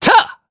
Zafir-Vox_Attack2_jp.wav